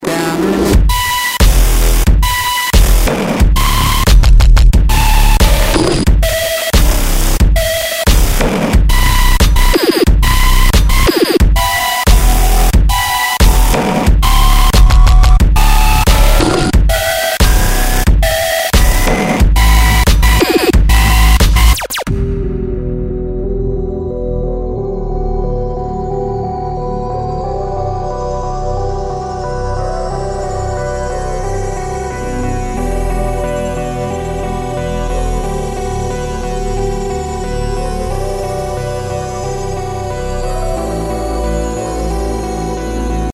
Категория: Рок рингтоны